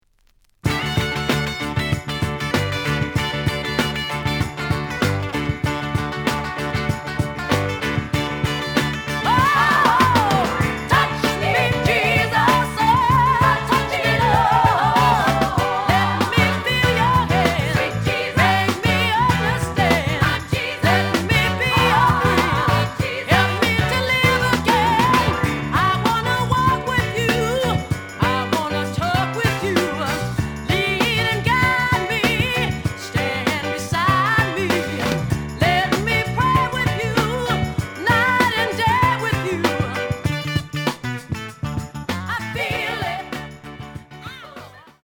試聴は実際のレコードから録音しています。
●Genre: Soul, 70's Soul